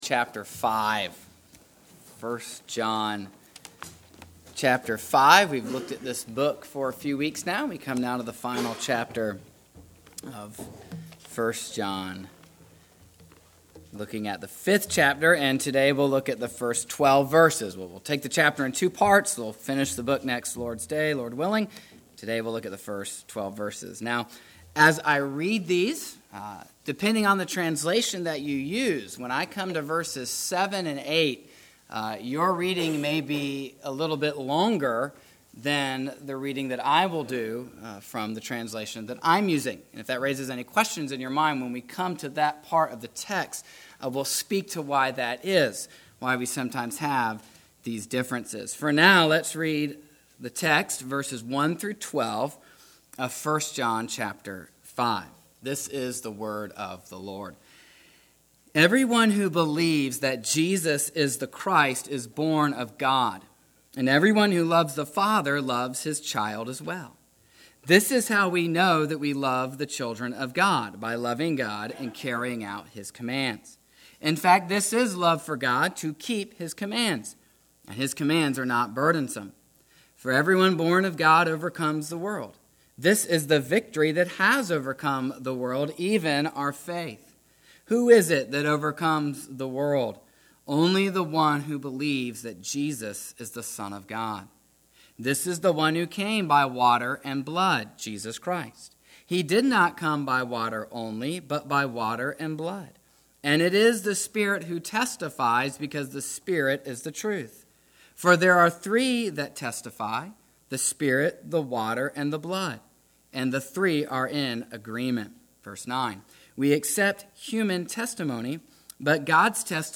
1 John 5:1-12 Service Type: Sunday Morning Bible Text